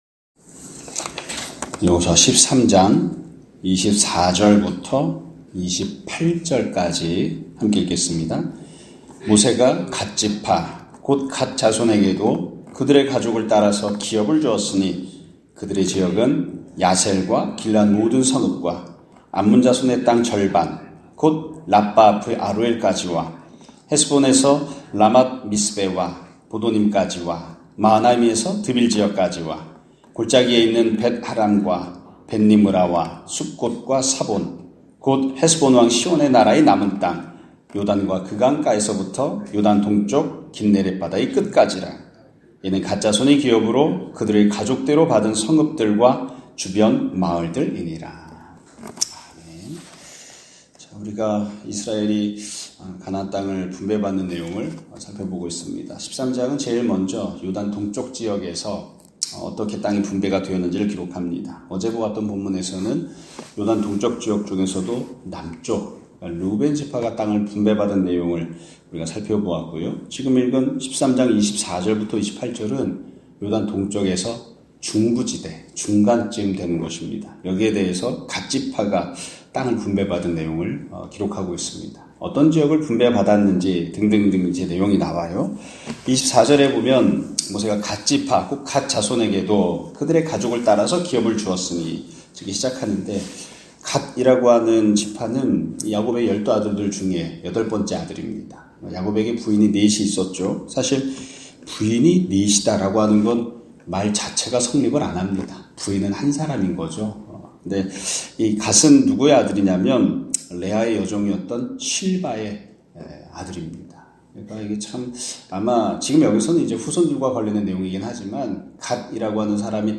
2024년 11월 21일(목요일) <아침예배> 설교입니다.